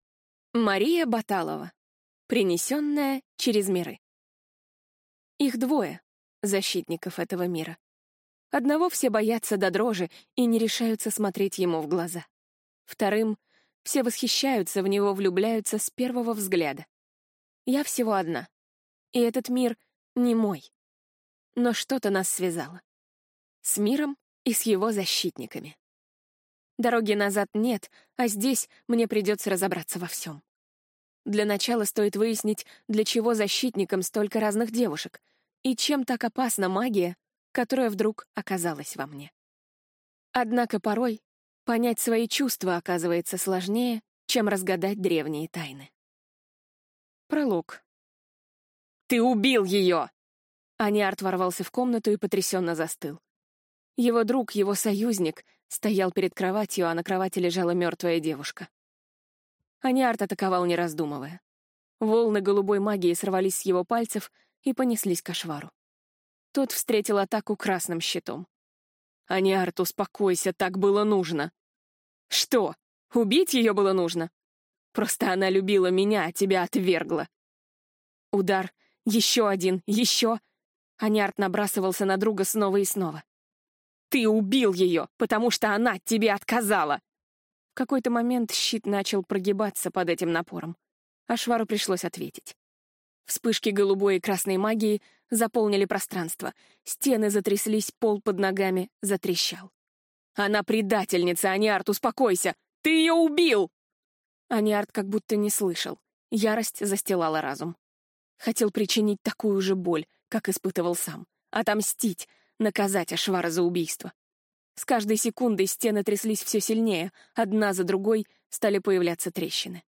Аудиокнига Принесенная через миры | Библиотека аудиокниг